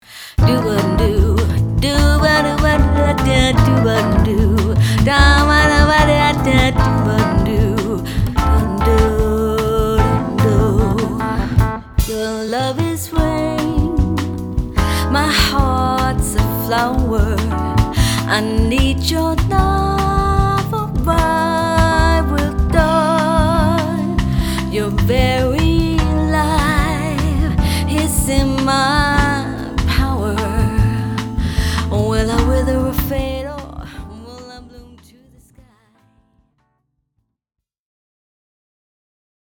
Hardware vs. Software im Blindtest
Hallo zusammen, selbes setting wie im letzten Thread... aber als Blindtest :) Eines der beiden ist komplett in SW gemixt, das andere mit Hardware.